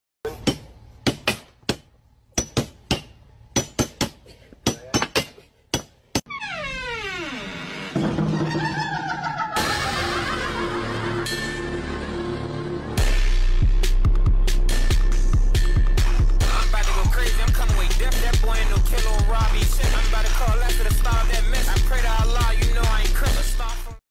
ranking the best random meme sound effects free download